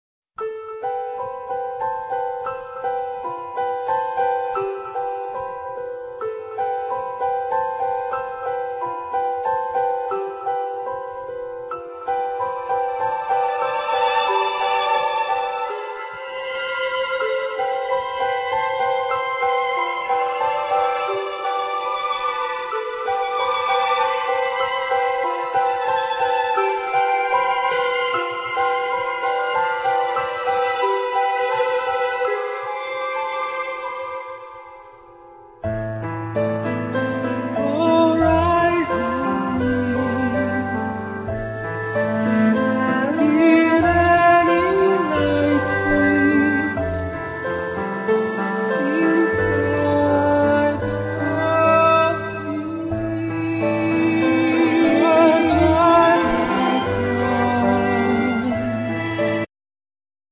Piano
Voice
Violin